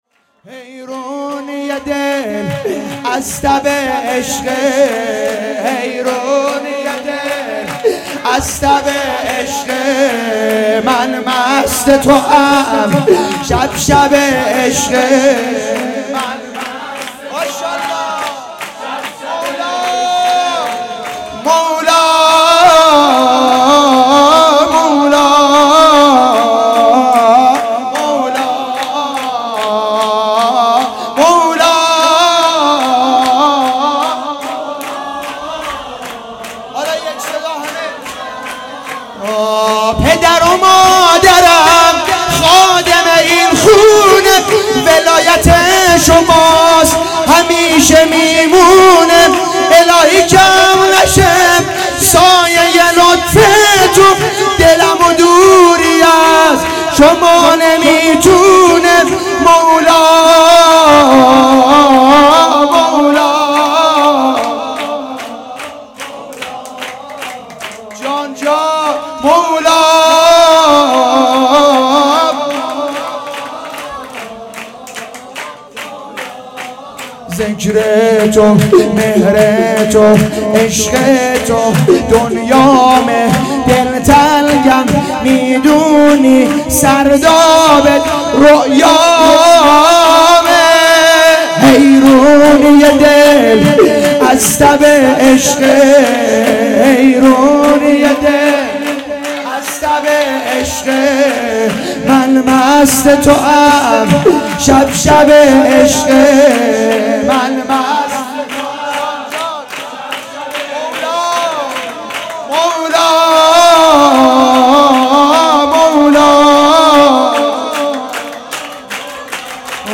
شور
جشن ولادت امام حسن عسکری علیه السلام